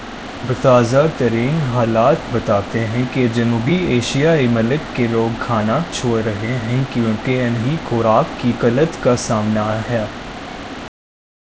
Spoofed_TTS/Speaker_07/271.wav · CSALT/deepfake_detection_dataset_urdu at main